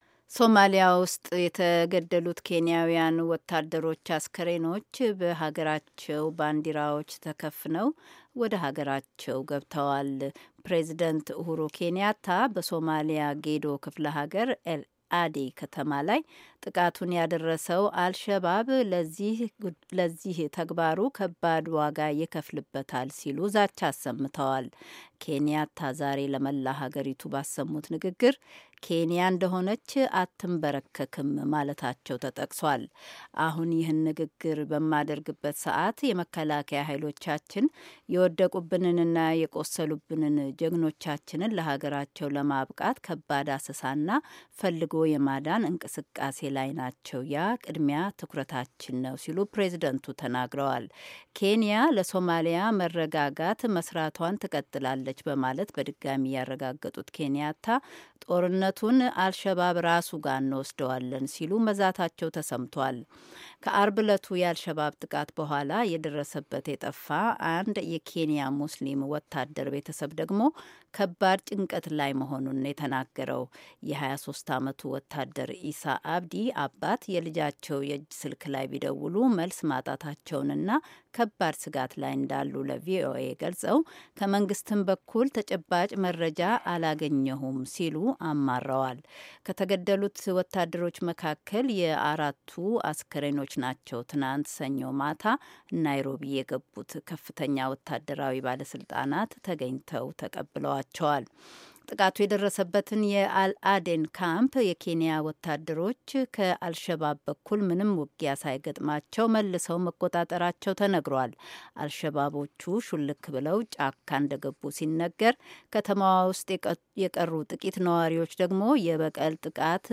አንዳንድ ኬንያውያን ሃገራችን መከላከያ ሃይሎችዋን ከሶማሊያ ታውጣ በማለት የጠየቁ ሲሆን ፕሬዚደንት ኬንያታ በበኩላቸው "ይህን የሰው ዘር ጠላት ለመውጋት በአንድነት መቆም አለብን" ሲሉ ተማጽነዋል። የዜና ዘገባውን ከዚህ በታች ካለው የድምጽ ፋይል ያድምጡ።